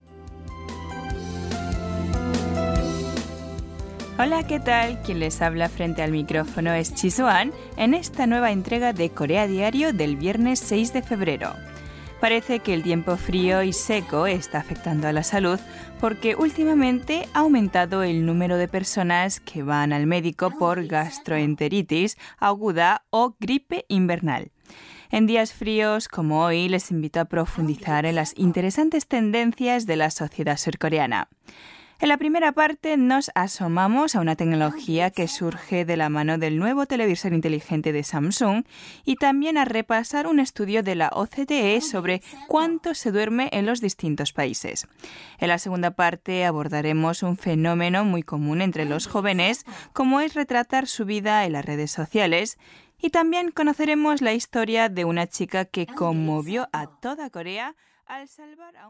스페인여 유럽지수나레이션 기본톤 - StudioMonkey
스페인여_유럽지수나레이션_기본톤.mp3